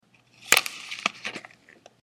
jablko_stavnate.mp3